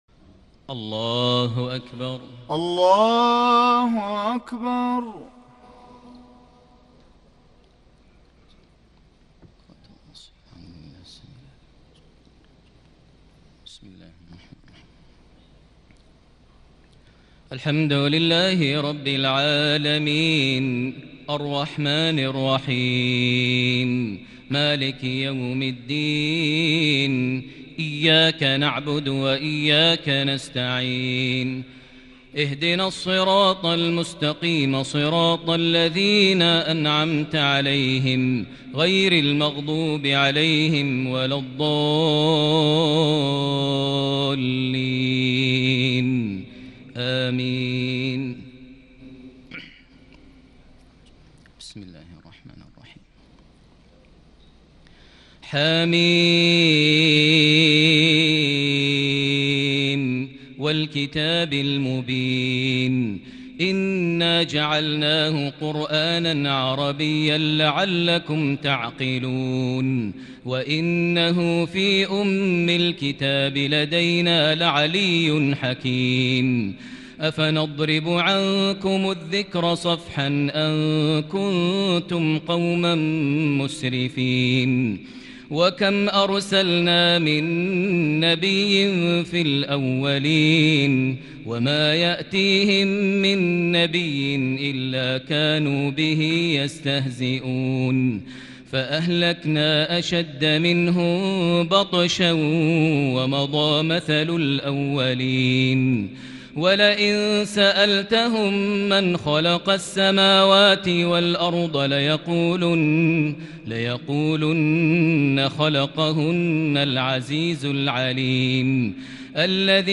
تهجد ليلة 26 رمضان 1441هـ سورة الزخرف كاملة | Tahajjud 26 st night Ramadan 1441H Surah Az-Zukhruf > تراويح الحرم المكي عام 1441 🕋 > التراويح - تلاوات الحرمين